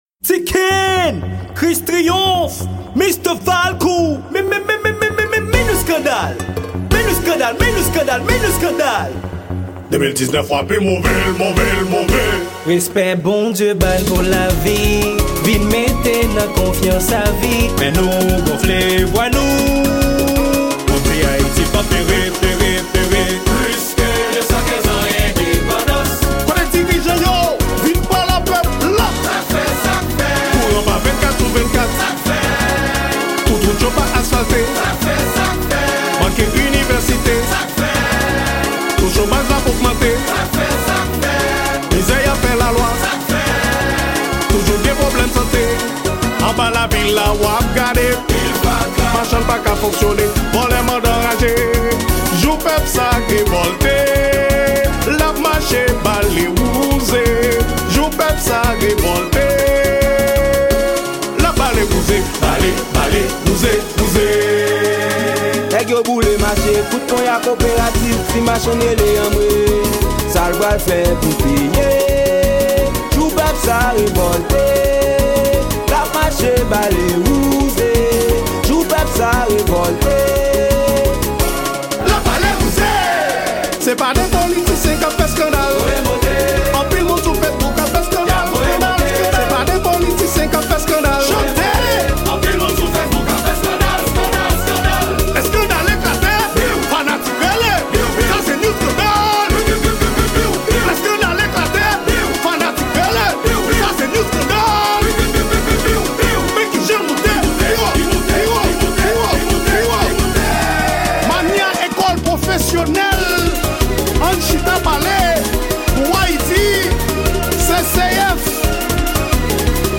Genre: kanaval.